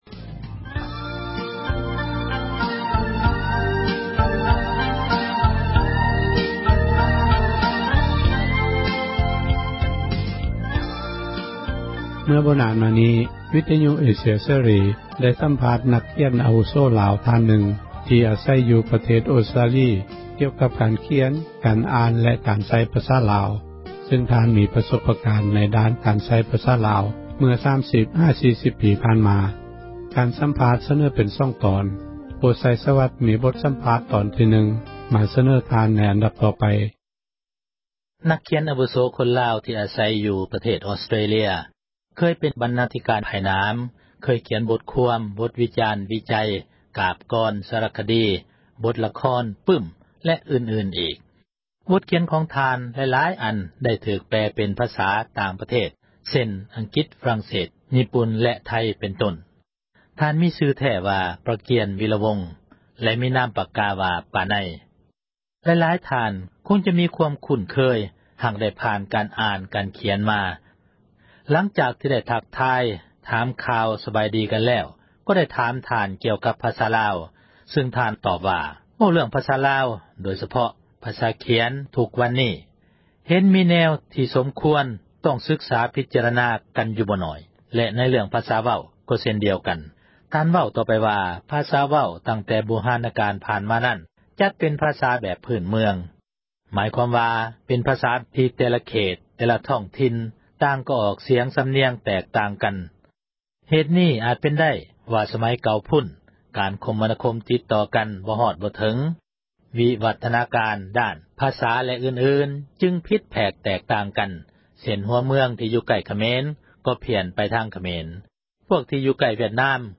ສັມພາດນັກຂຽນລາວ ທີ່ Australia